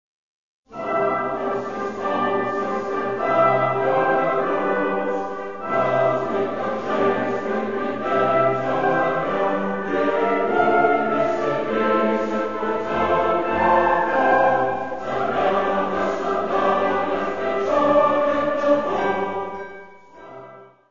: stereo; 12 cm + Libreto
Music Category/Genre:  Classical Music
Chorus